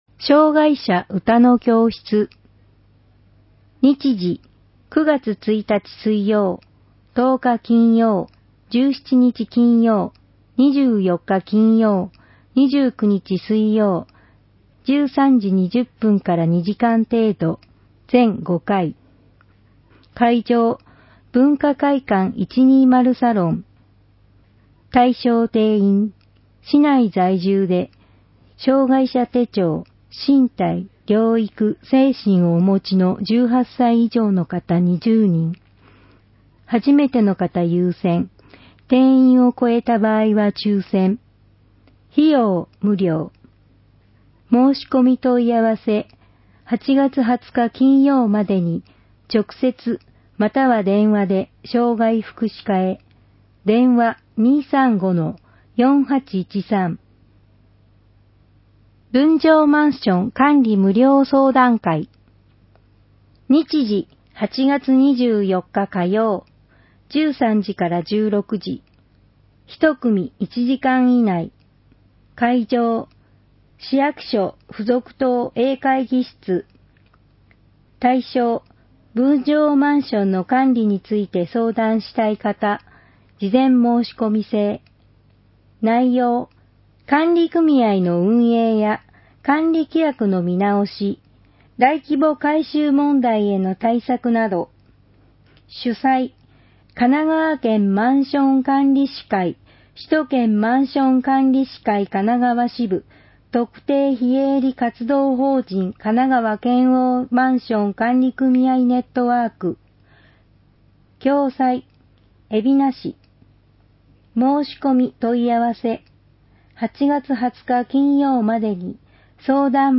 音声版は、音声訳ボランティア「矢ぐるまの会」のご協力により、同会が視覚障がい者の方のために作成したものを登載しています